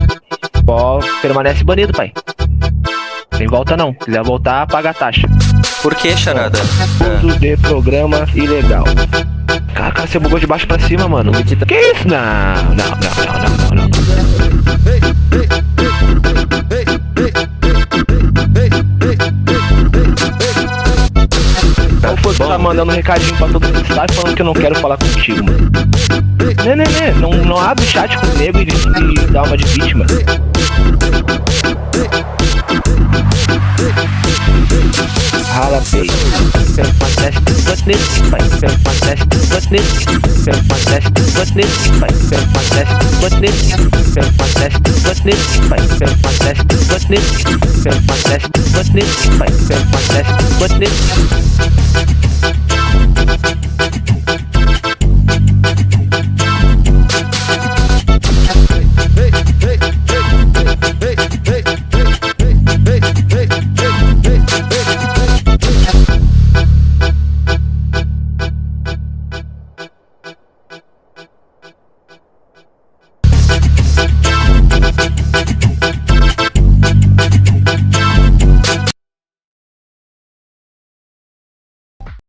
2025-01-03 14:42:33 Gênero: MPB Views